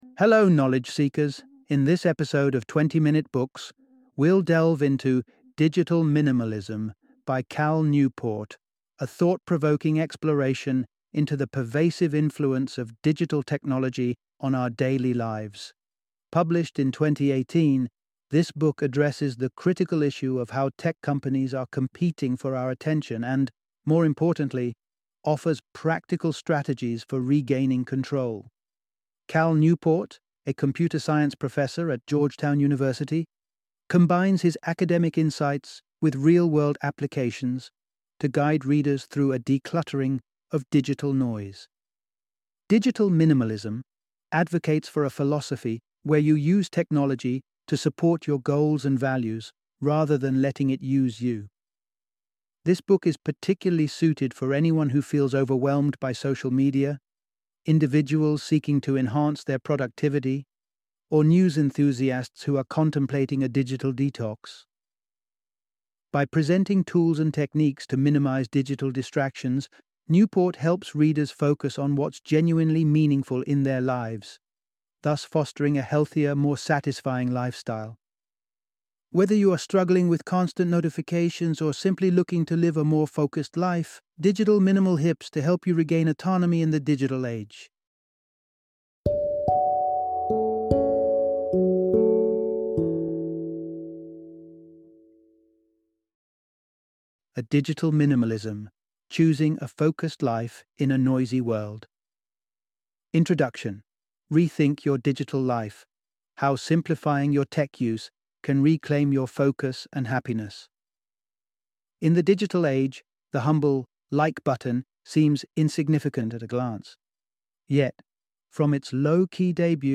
Digital Minimalism - Audiobook Summary